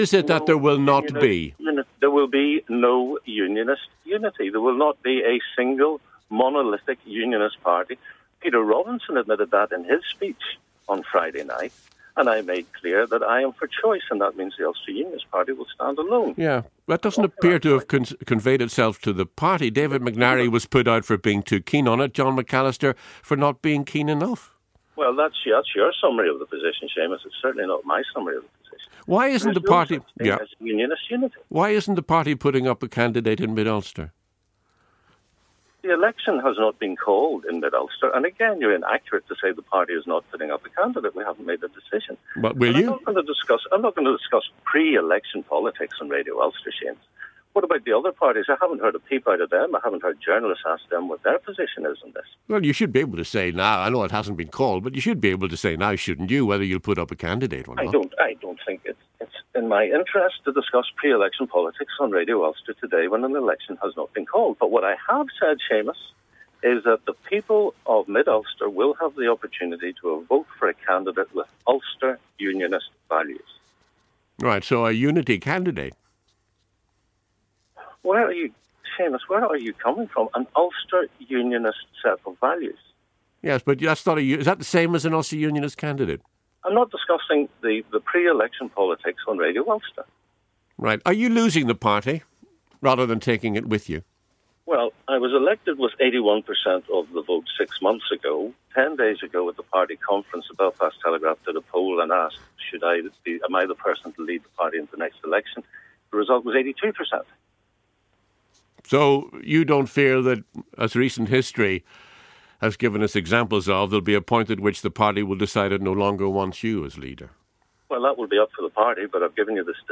speaks to Ulster Unionist leader, Mike Nesbitt, about why he sacked his deputy, John McCallister.